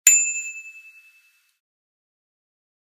bicycle-bell_09
bell bells bicycle bike bright chime chimes clang sound effect free sound royalty free Memes